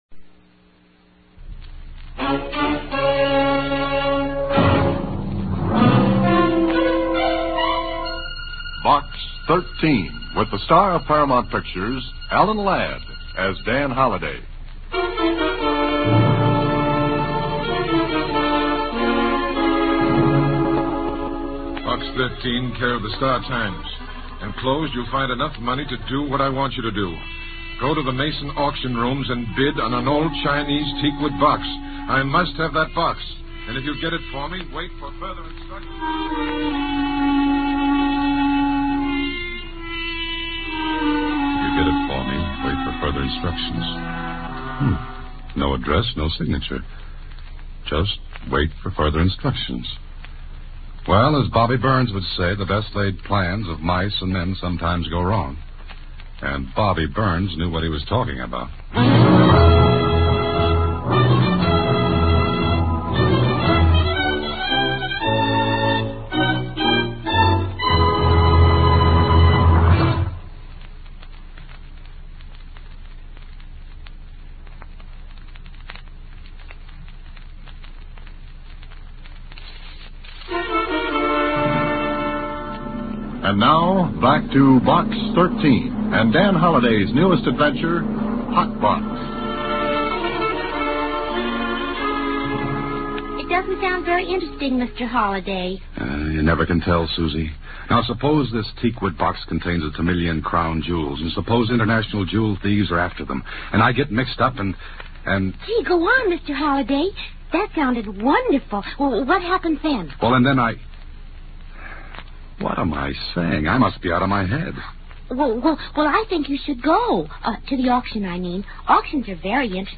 Box 13 Radio Program, Starring Alan Ladd